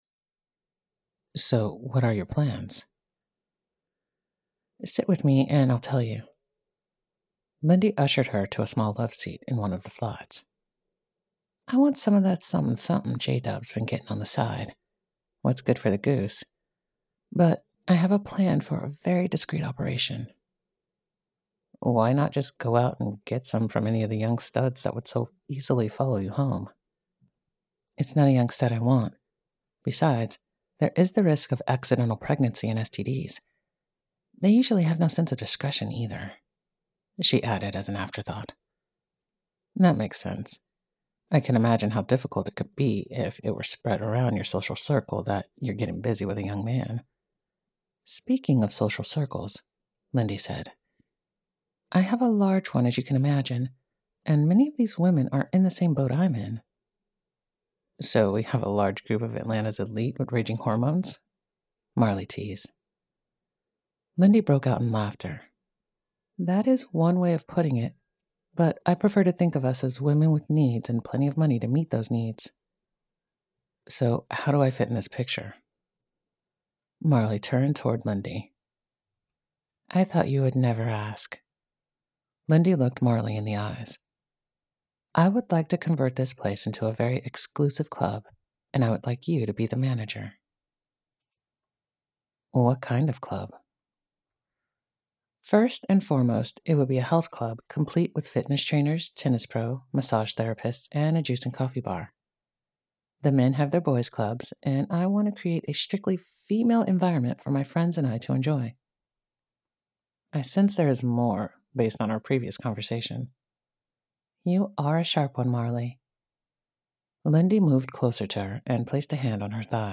The Trophy Wives Club by Ali Spooner [Audiobook]